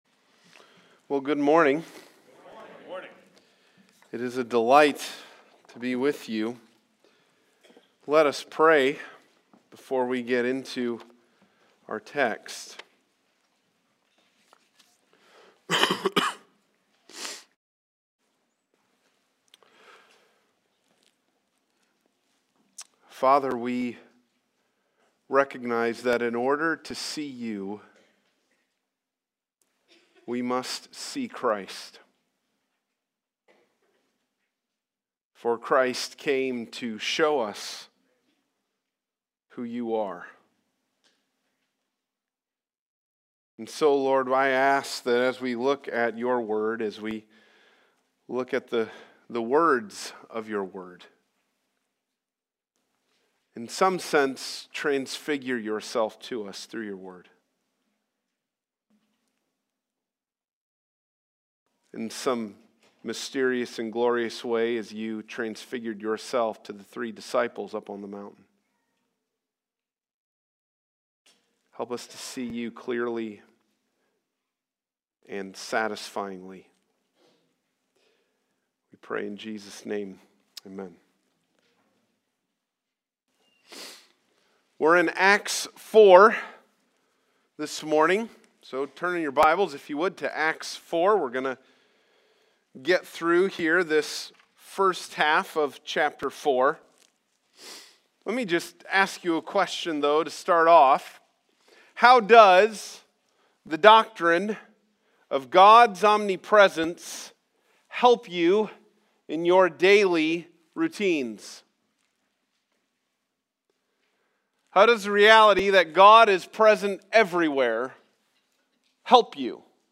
Passage: Acts 4:1-22 Service Type: Sunday Morning « Suffering and Boldness in the Early Church